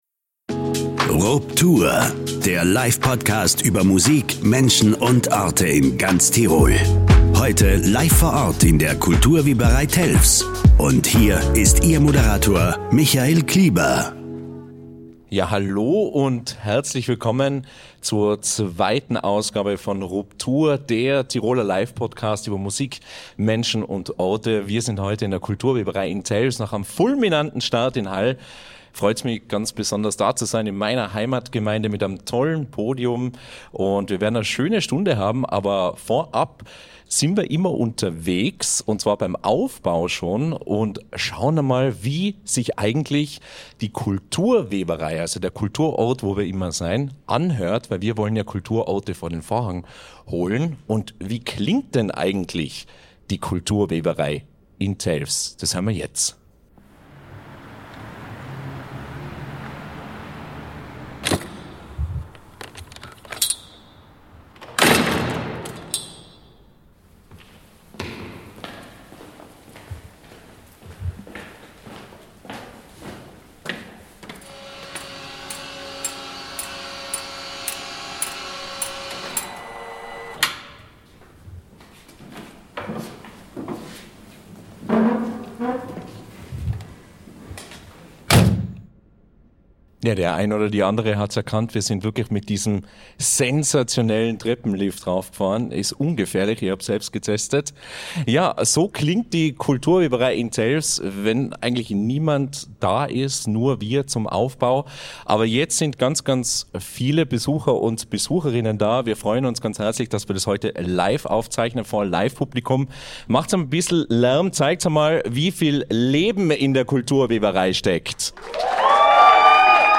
RUPTUR zu Gast in der Kultur Weberei Telfs ~ RUPTUR – Tirols Live-Podcast über Musik, Menschen und Orte. Podcast